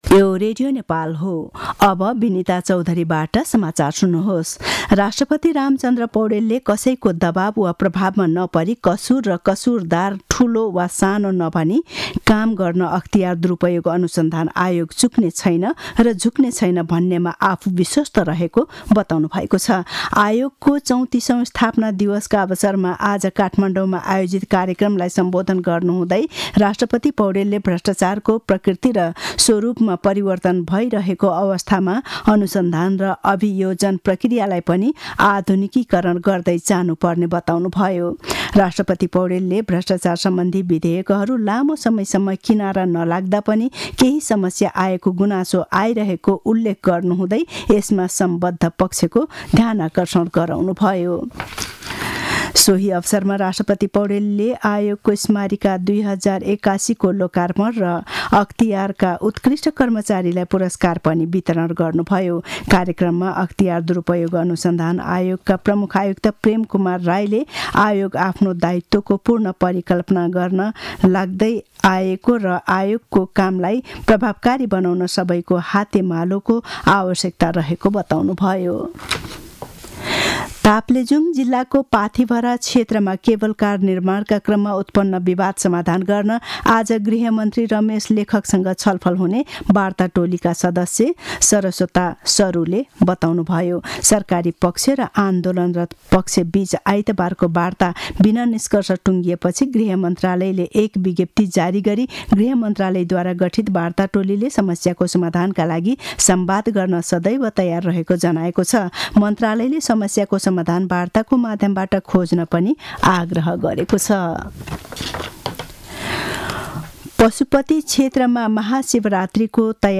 दिउँसो १ बजेको नेपाली समाचार : २९ माघ , २०८१
1-pm-news-1-4.mp3